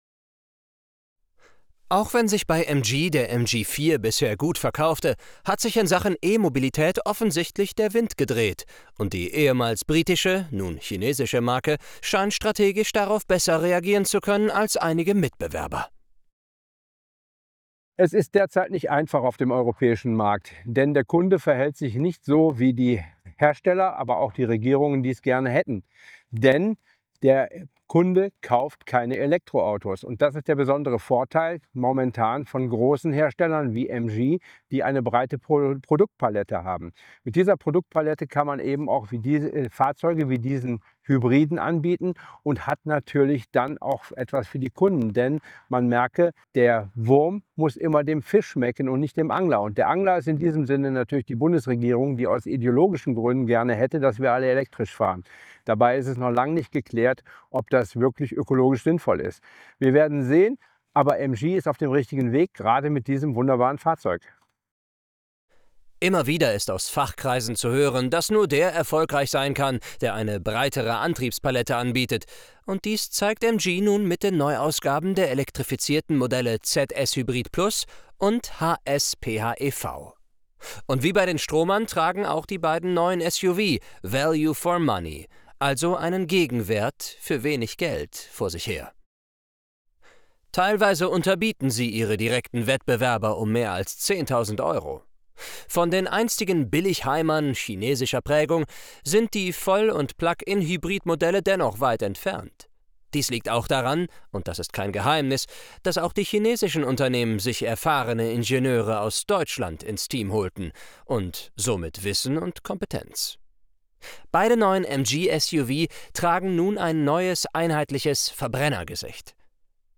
Downloads Deutsch (1080p H.264) Deutsch (720p H.264) Deutsch (360p H.264) Deutsch (Voiceover WAV) Atmo/Clean (1080p H.264) Atmo/Clean (720p H.264) Atmo/Clean (360p H.264)